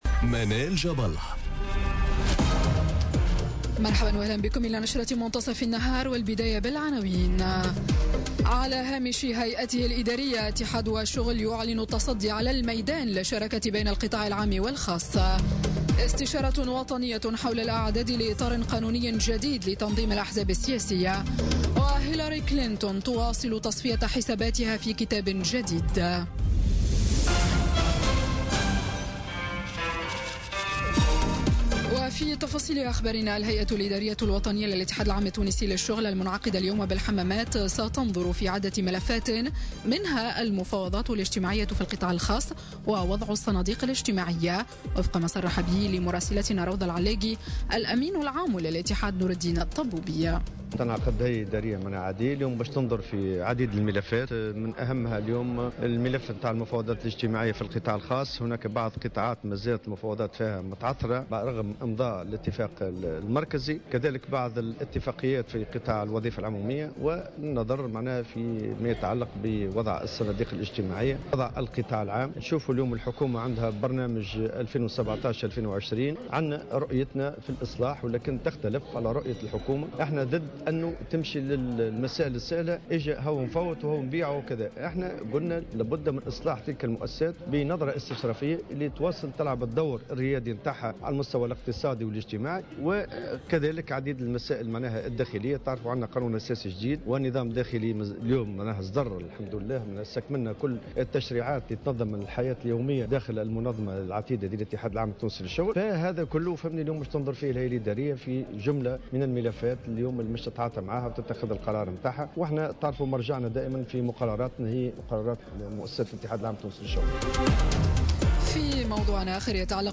نشرة أخبار منتصف النهار ليوم الثلاثاء 12 سبتمبر 2017